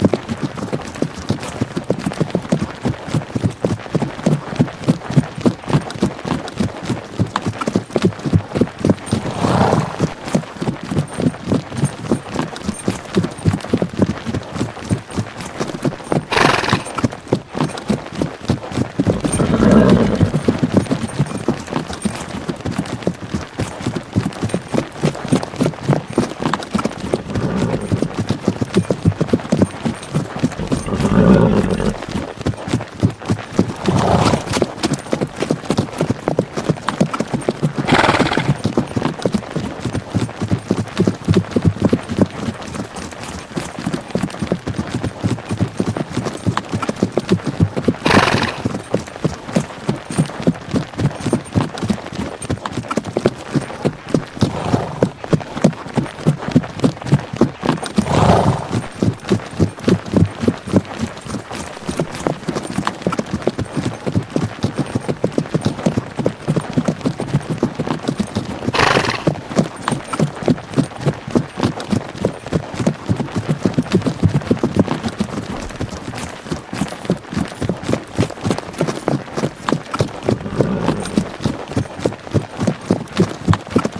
Конь мчится по траве